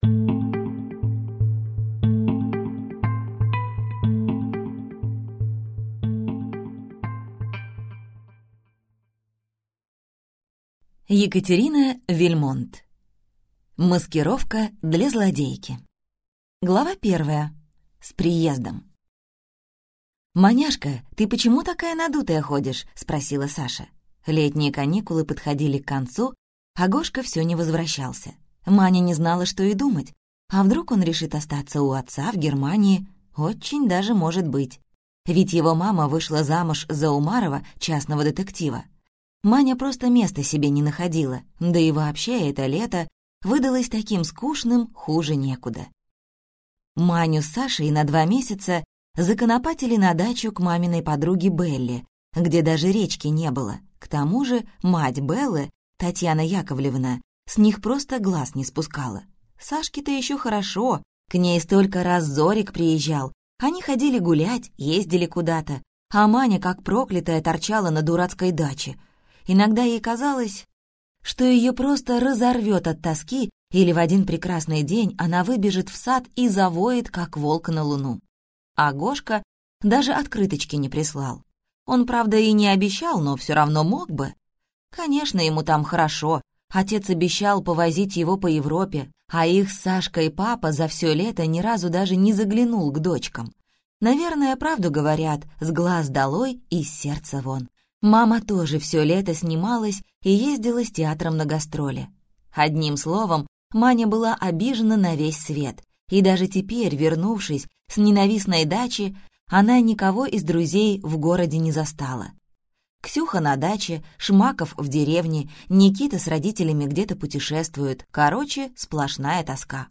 Аудиокнига Маскировка для злодейки | Библиотека аудиокниг